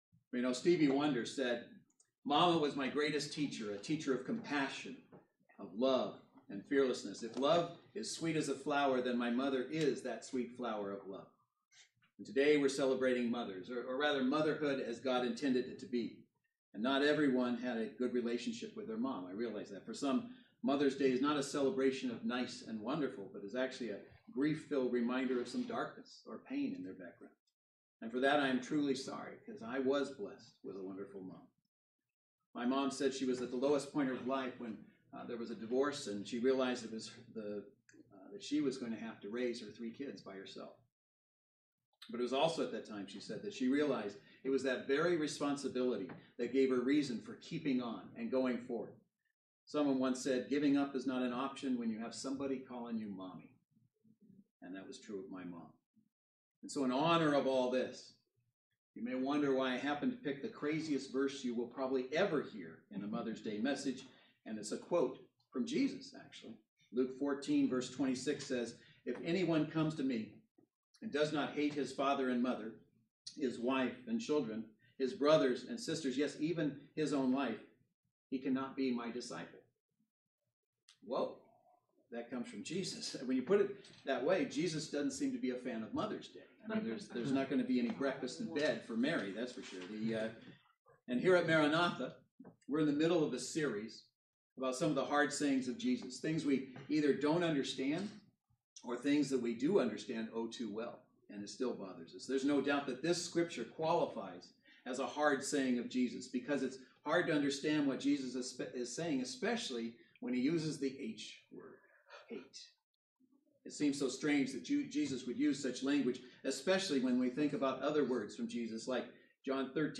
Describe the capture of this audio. Series: Did Jesus Really Say That? Service Type: Saturday Worship Service Speaker